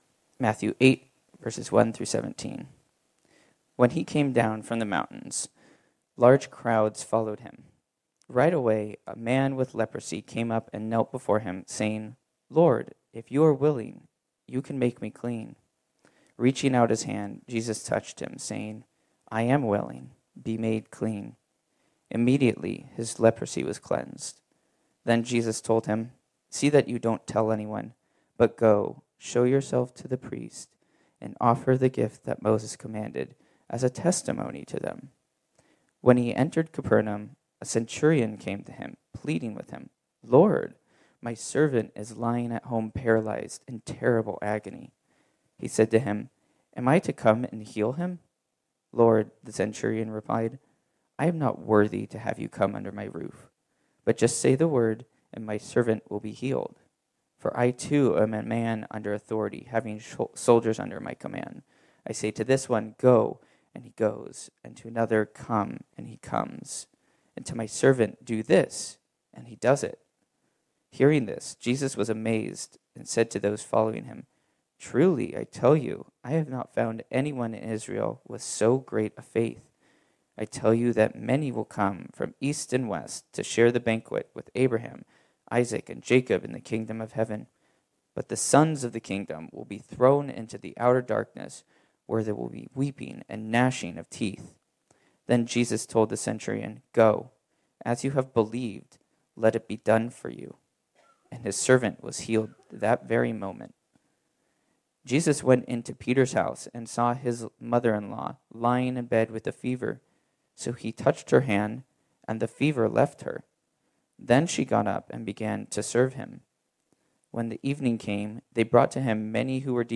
This sermon was originally preached on Sunday, April 7, 2024.